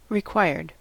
Ääntäminen
Synonyymit due needful Ääntäminen US : IPA : [rɪˈkwa.ɪə(r)d] Haettu sana löytyi näillä lähdekielillä: englanti Käännös Adjektiivit 1. tarpeellinen Required on sanan require partisiipin perfekti.